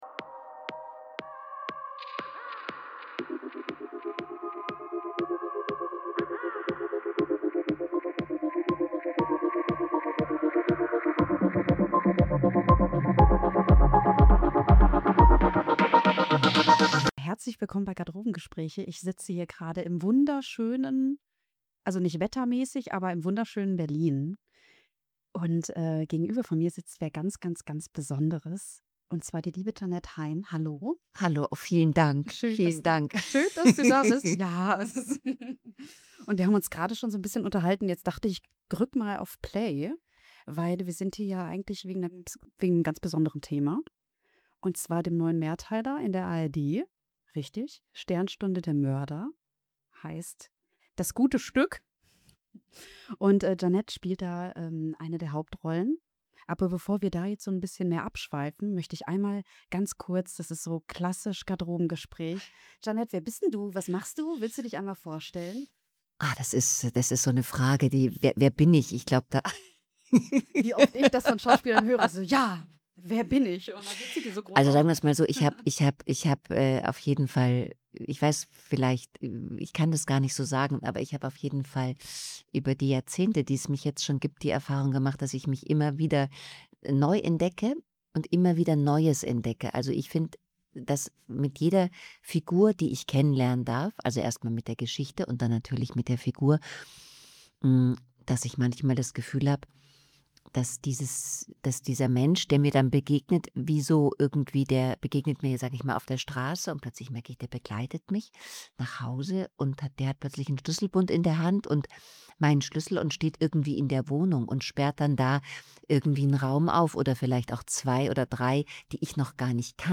In dieser Folge von Garderobengespräche ist die Schauspielerin Jeanette Hain zu Gast. Sie spricht über ihren Werdegang, ihre Arbeit vor der Kamera und die besonderen Herausforderungen ihres Berufs.